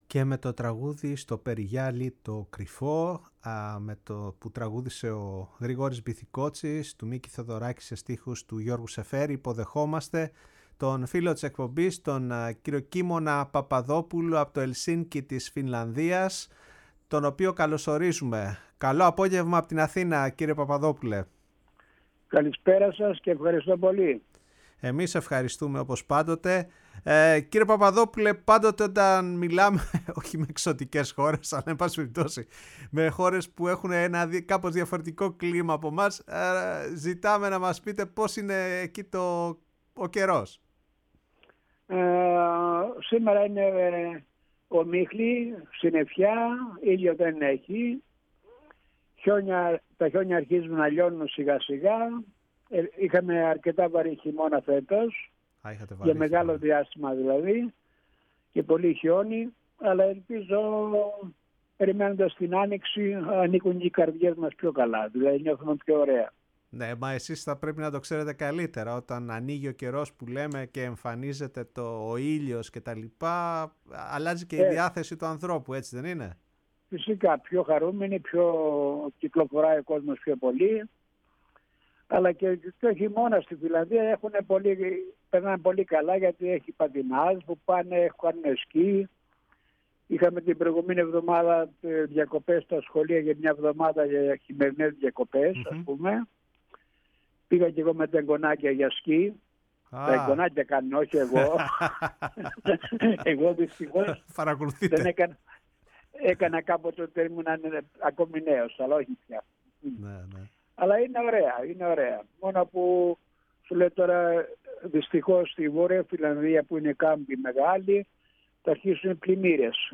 ΣΥΝΕΝΤΕΥΞΕΙΣ